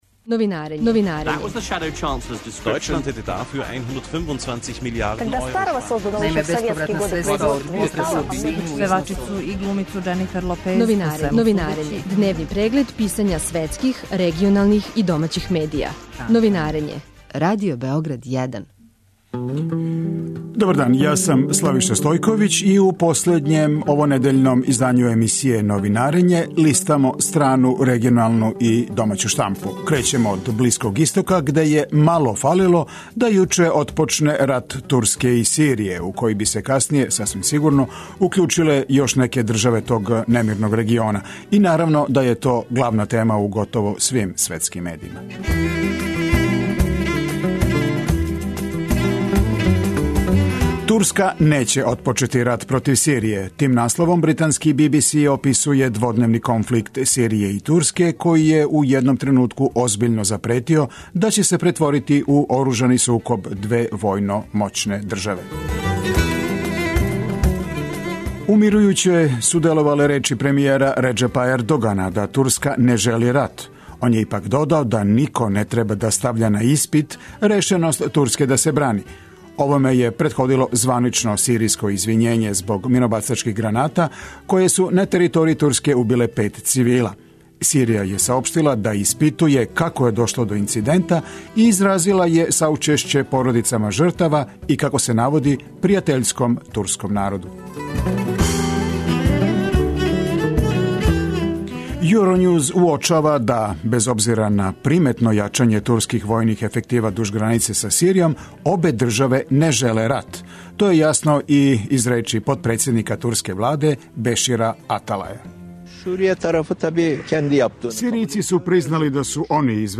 Преглед штампе у трајању од 15 минута. Чујте које приче су ударне овога јутра за највеће светске станице и листове, шта се догађа у региону и шта пише домаћа штампа.